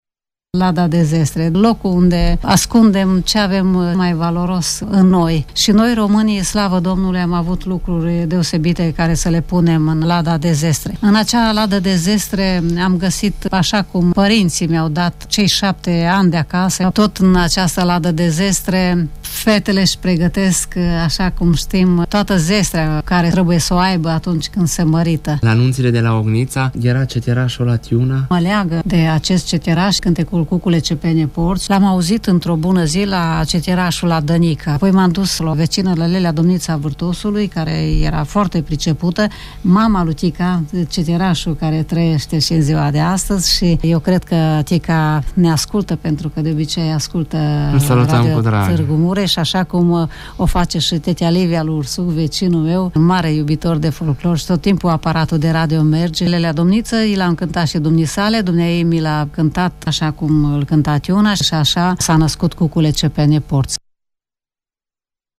în direct la Radio Tîrgu-Mureş, în emisiunea” Pe cărarea dorului”